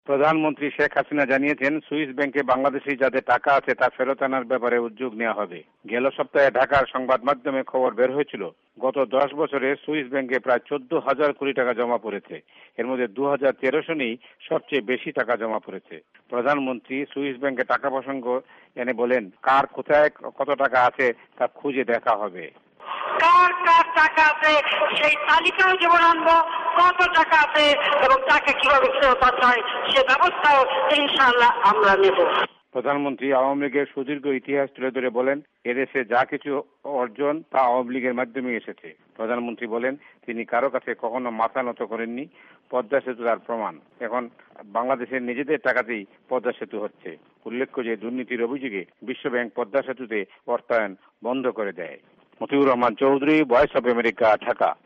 Prime Minister Hasina Speaks